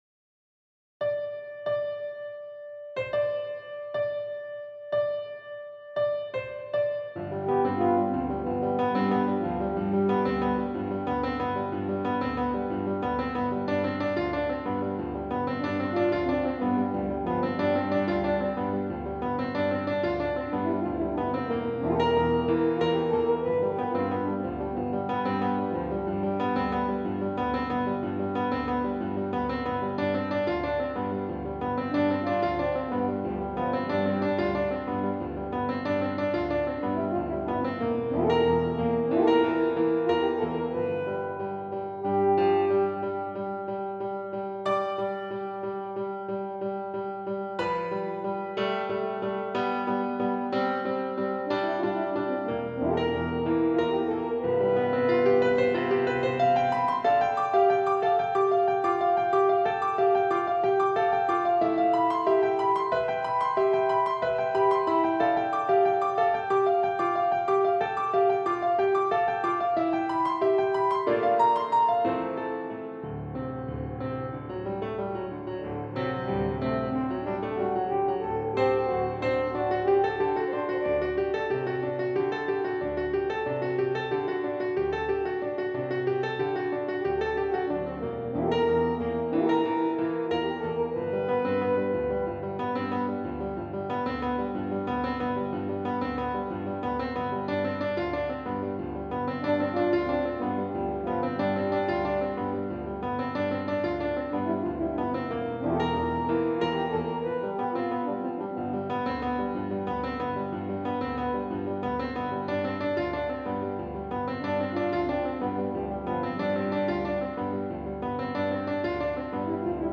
Psalm 150 - Accompaniment TrackSolo Accompaniment Track